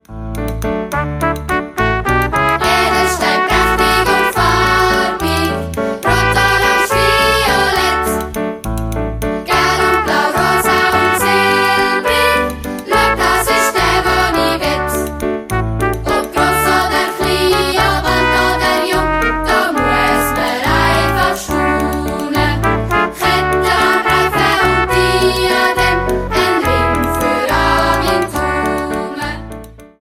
Schulmusical